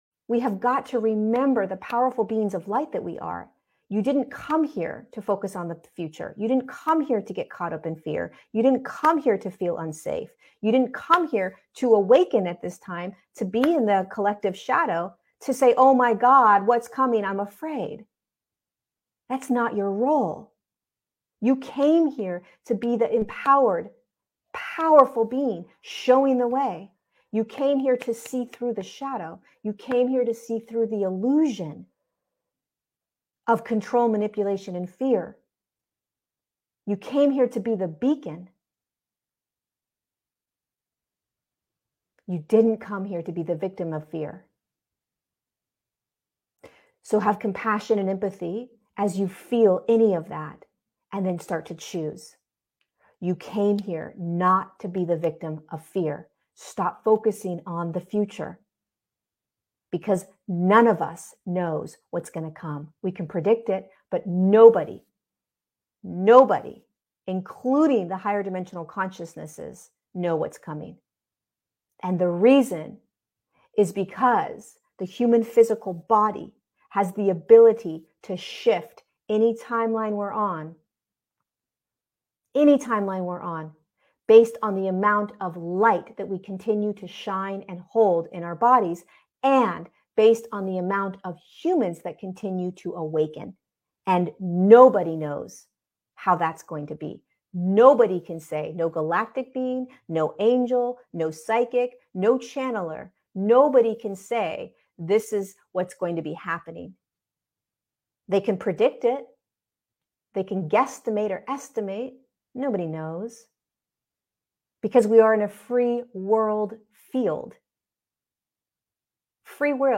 You Came Here For This! ⚡❤ This is a short clip from my most recent Patreon live event.